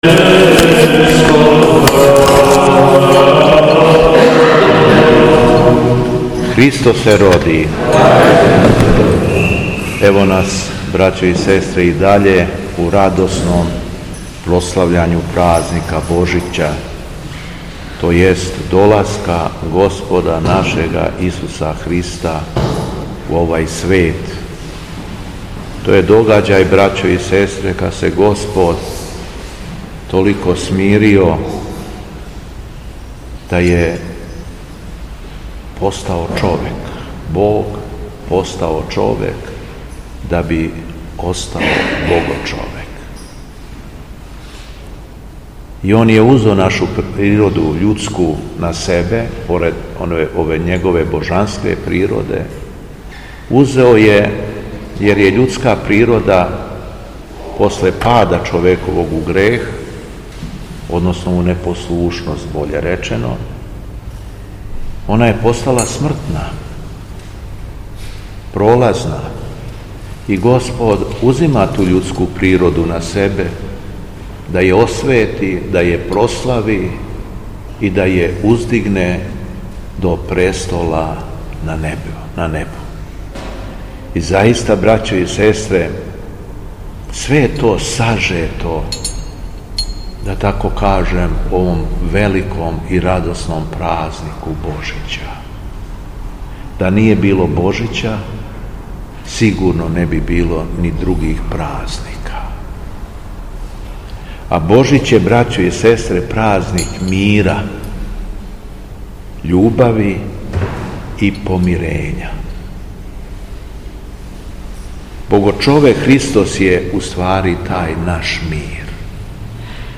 Беседа Његовог Високопреосвештенства Митрополита шумадијског г. Јована
После прочитаног јеванђелског зачала Високопреосвећени Митрополит се обратио беседом сабраном народу рекавши: